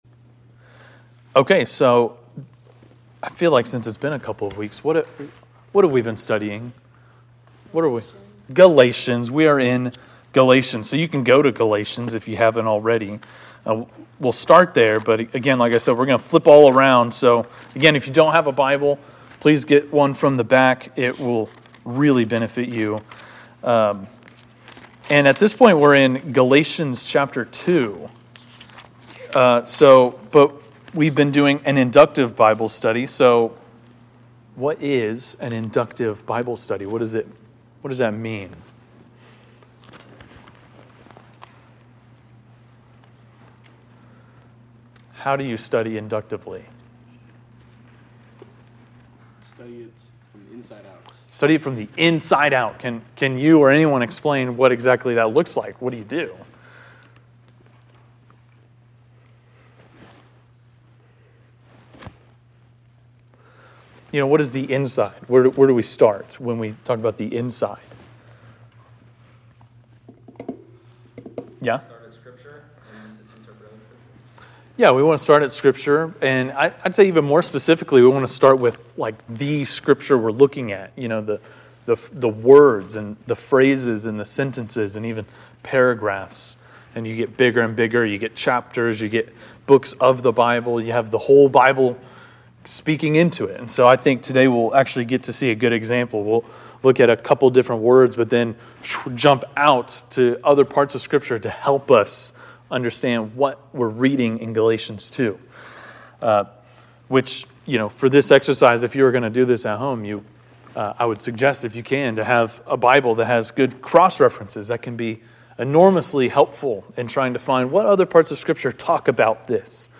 Galatians 2:15-16 (Inductive Bible Study)